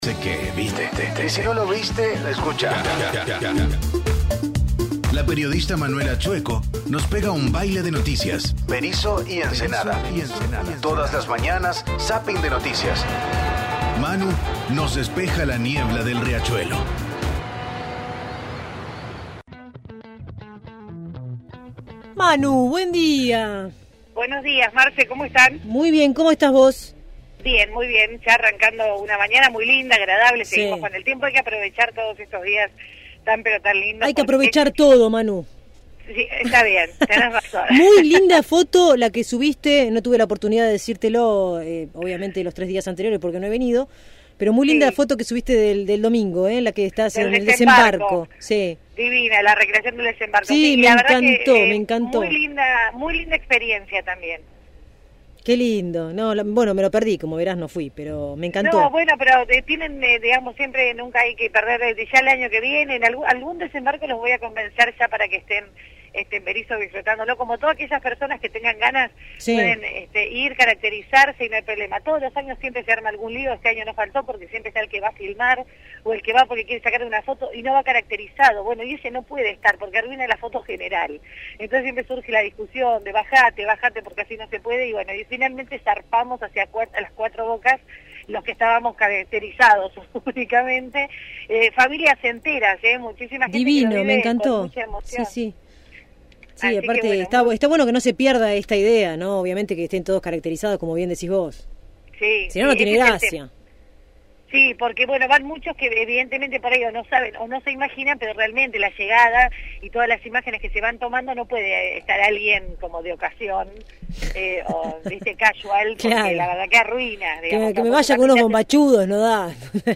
Informe de Berisso y Ensenada (17-9) – Radio Universidad